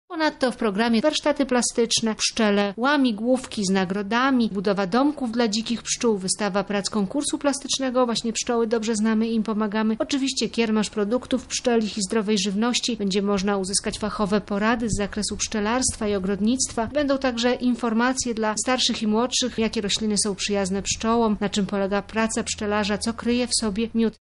– mówi